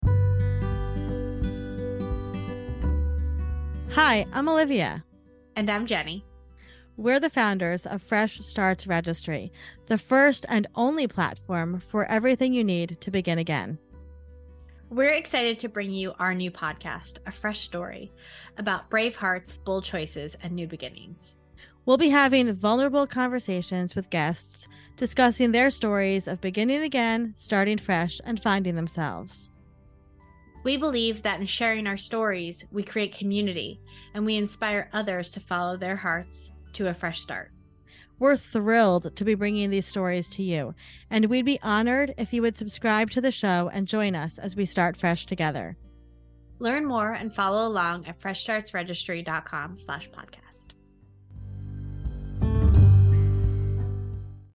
Trailer: